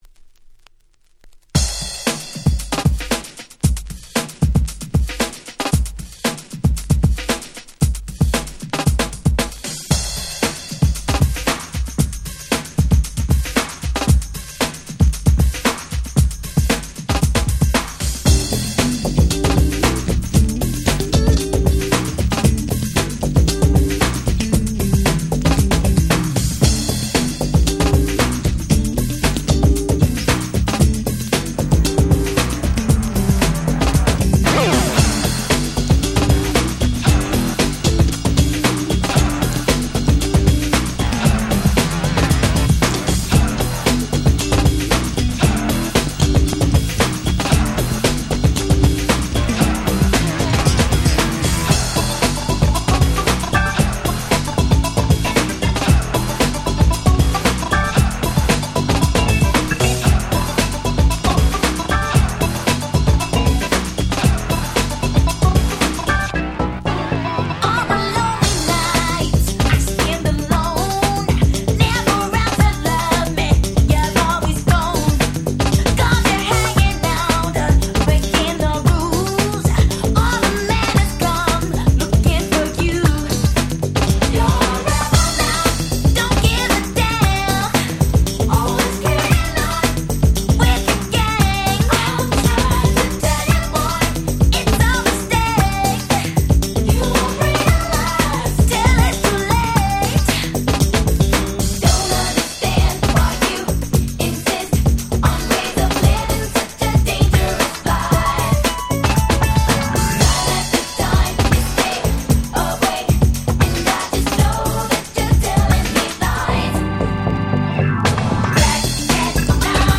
80's R&B Super Classics !!
New Jack Swing